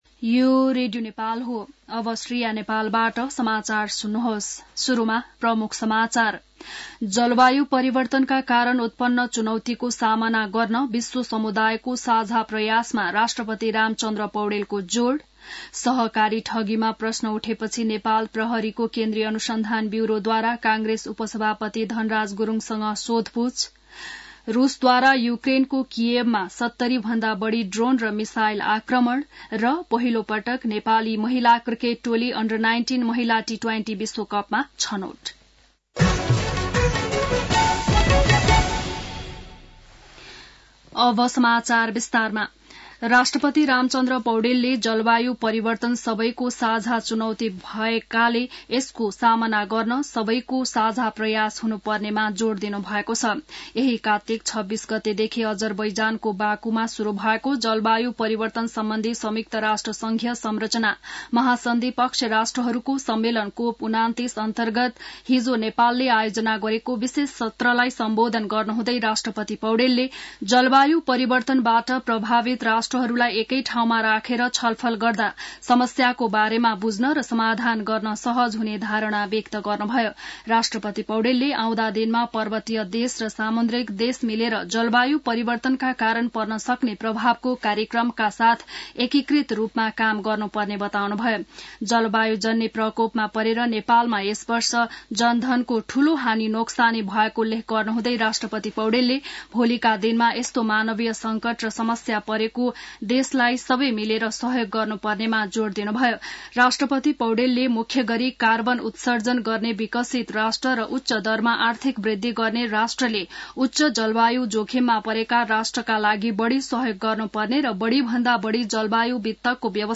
बिहान ९ बजेको नेपाली समाचार : ३० कार्तिक , २०८१